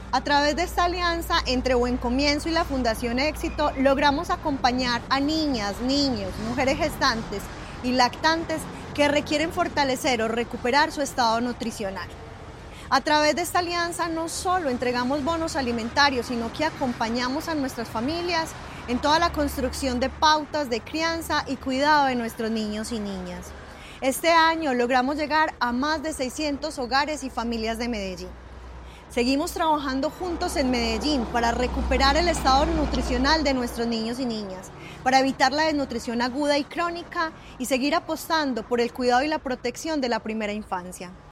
Declaraciones de la directora de Buen Comienzo, Diana Carmona Henao.